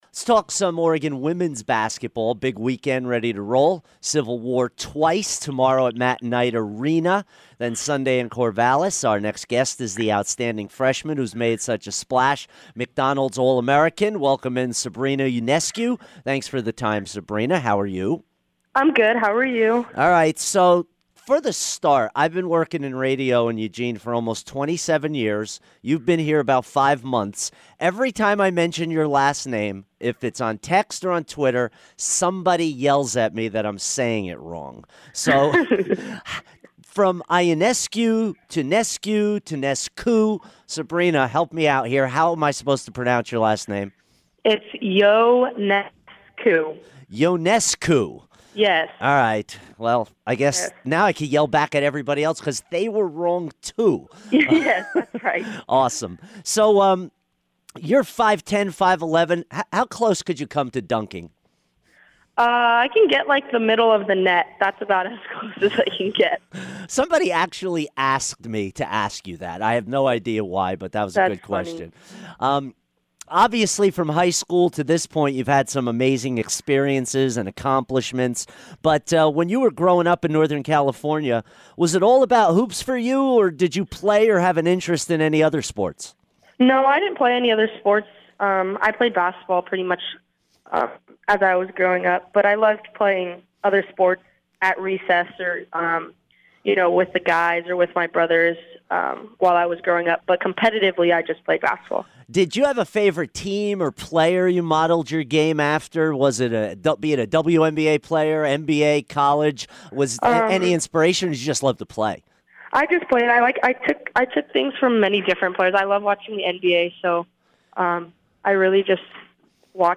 Sabrina Ionescu Interview 1-26-17